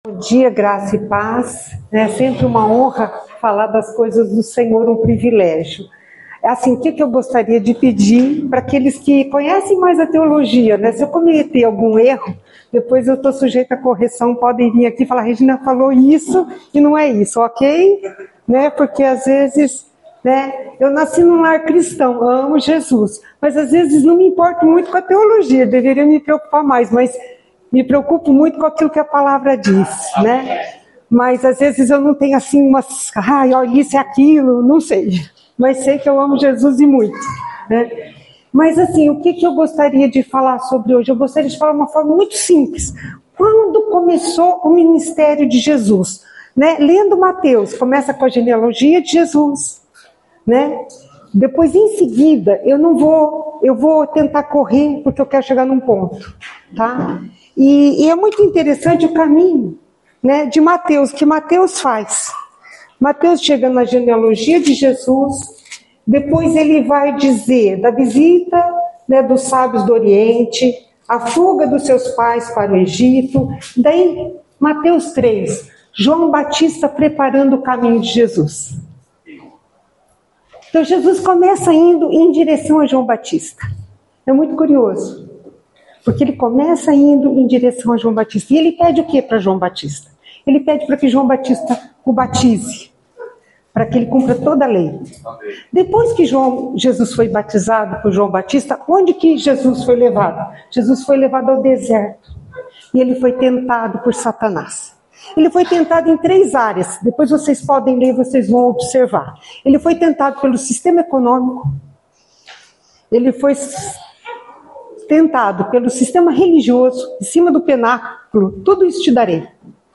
Palavra ministrada